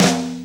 Index of /90_sSampleCDs/Best Service ProSamples vol.15 - Dance Drums [AKAI] 1CD/Partition A/SD 121-180